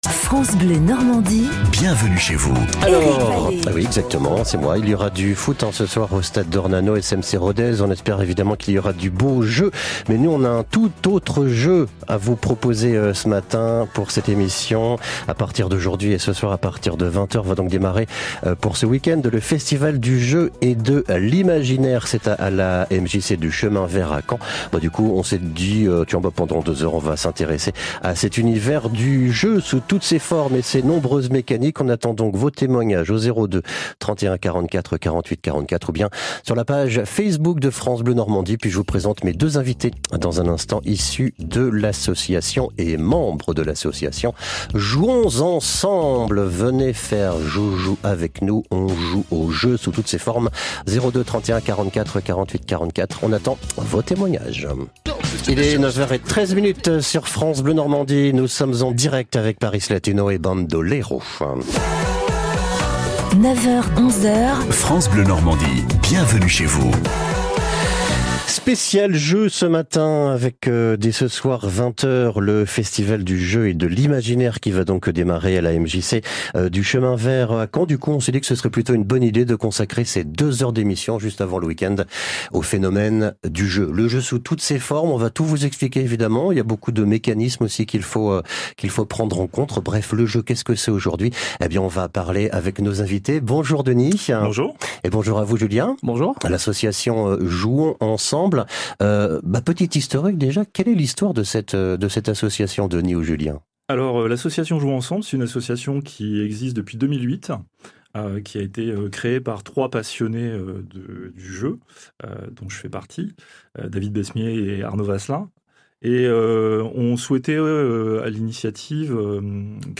Une interview sur le Festival du Jeu et de l’Imaginaire